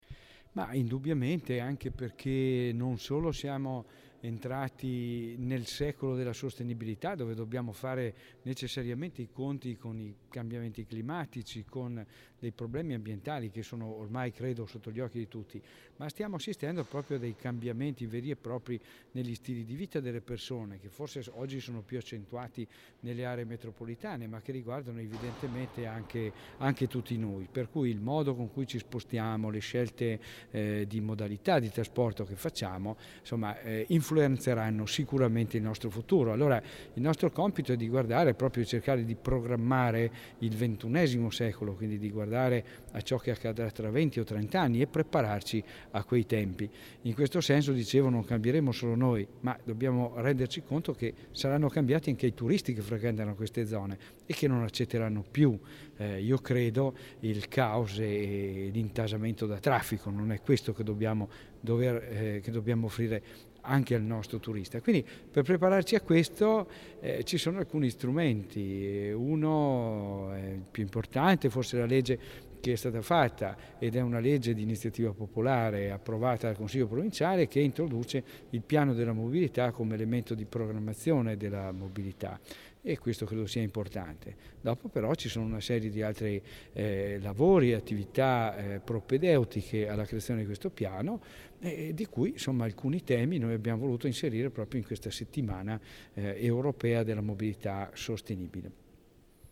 GILMOZZI_intervista_su_settimana_mobilita.mp3